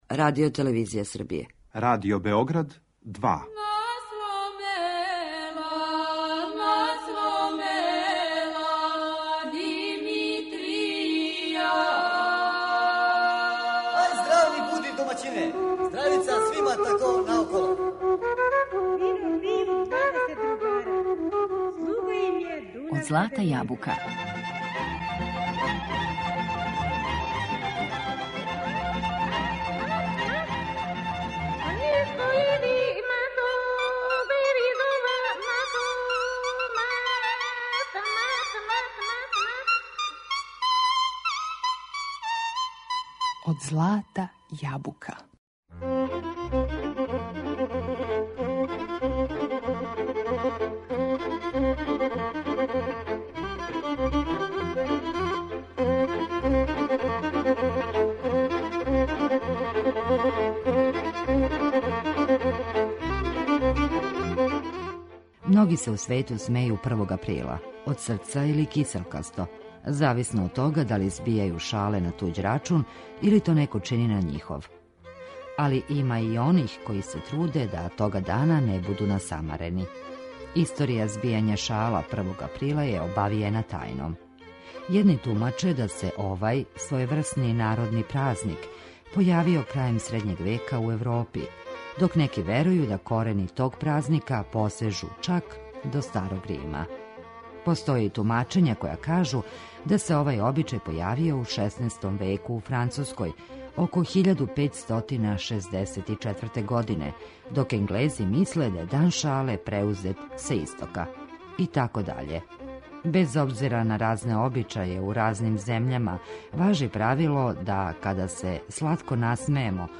Српске народне шаљиве приче и песме
Данас Вам у емисији Од злата јабука нудимо избор шаљивих народних прича које је сакупио Вук Стефановић Караџић и прегршт шаљивих народних песама из богате ризнице Тонског архива Радио Београда.